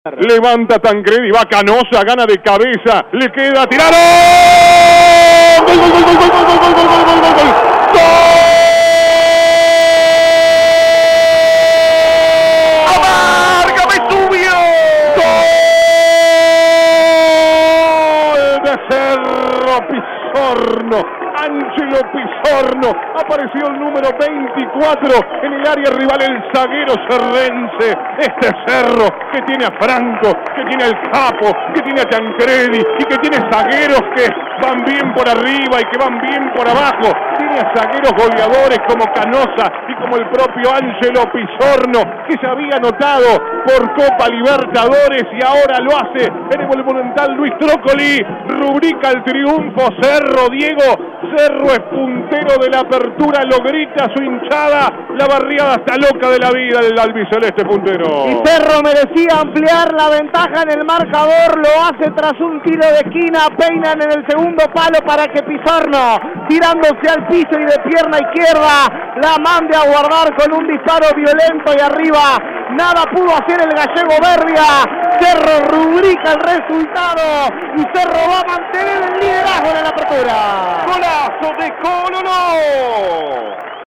Triunfos de Nacional y Peñarol pero también sumaron de a 3 Cerro, Defensor Sp. y Wanderers para seguir bien arriba en la tabla. Reviví los relatos de los goles.